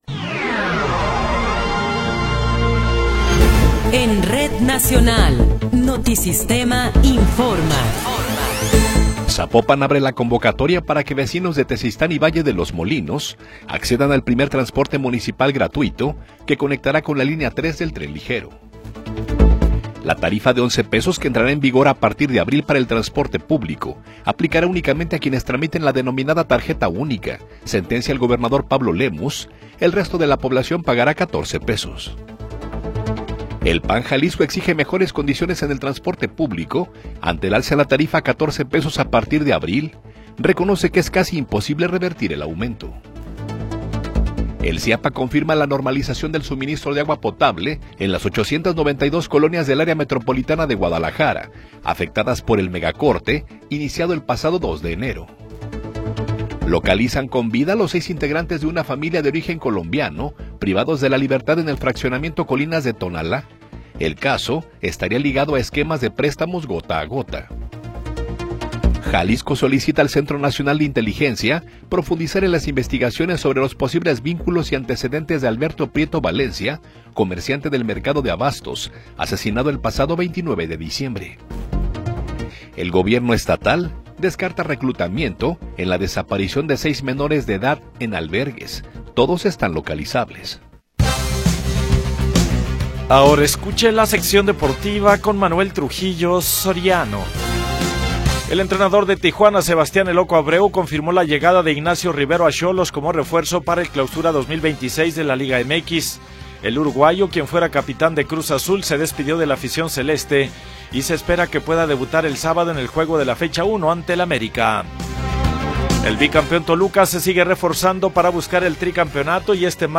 Noticiero 21 hrs. – 6 de Enero de 2026
Resumen informativo Notisistema, la mejor y más completa información cada hora en la hora.